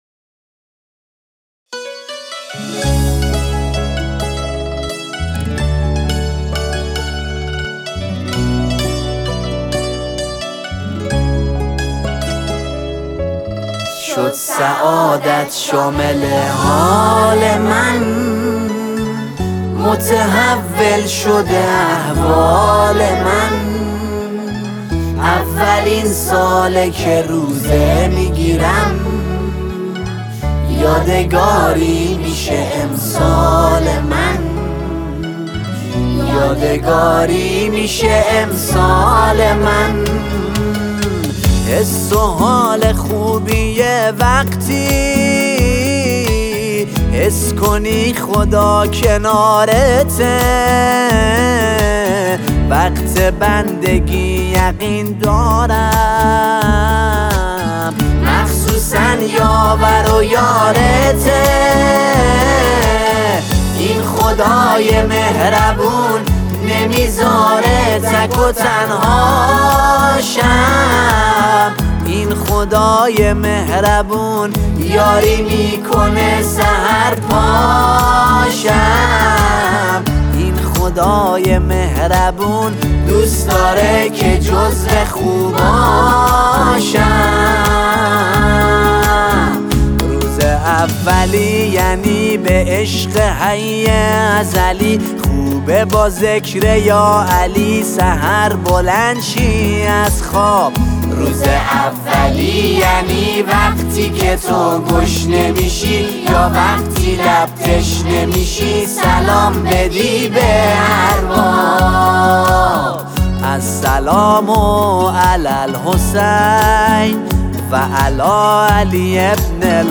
نماهنگ دلنشین و پرانرژی